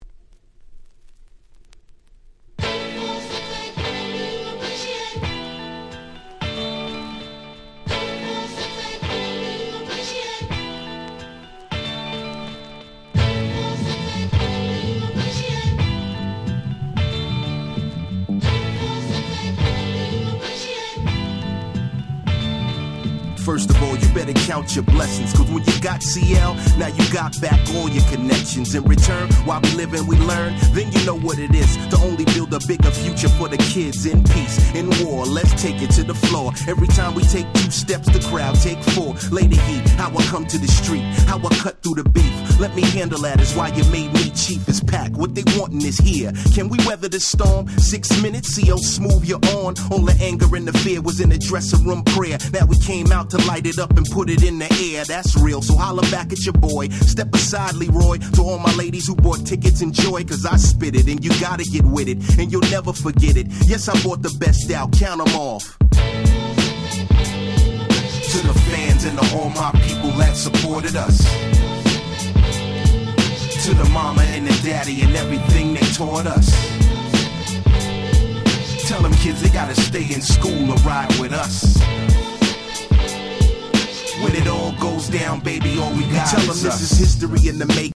04' Very Nice Hip Hop !!
00's ブーンバップ